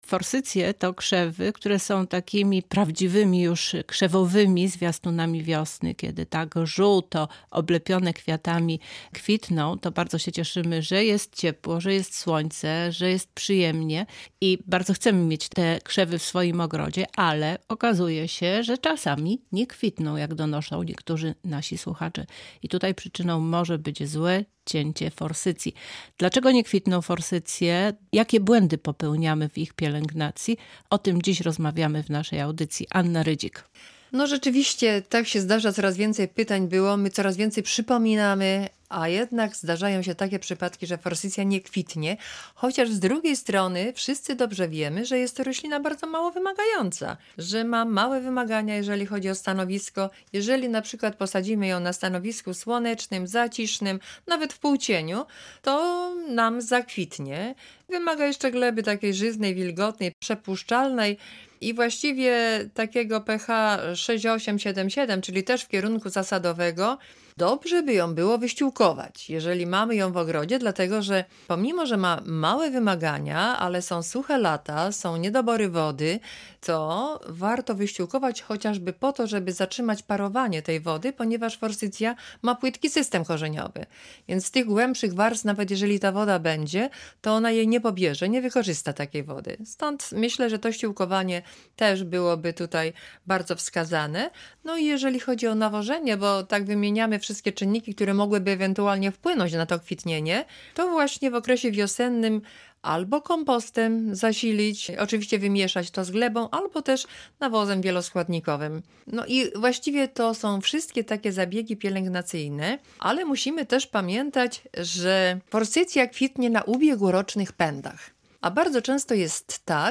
Całej rozmowy